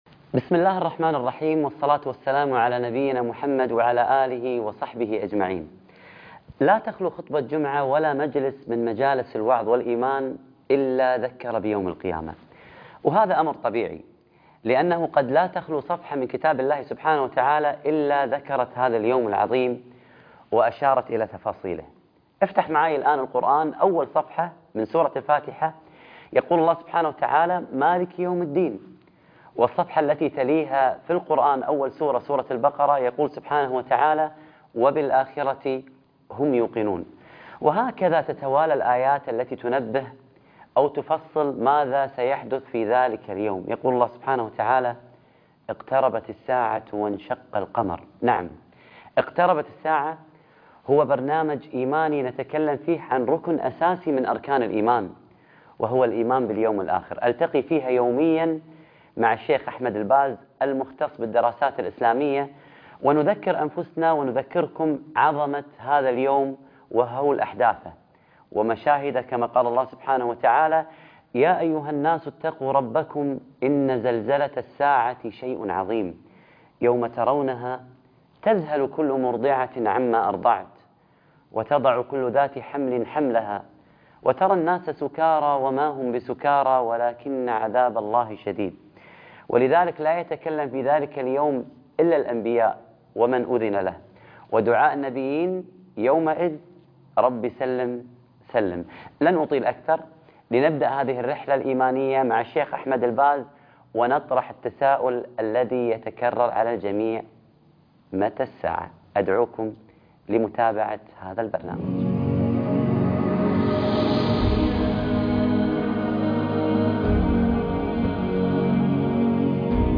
اقتربت الساعة - القاريء فهد الكندري